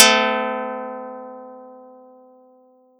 Audacity_pluck_3_14.wav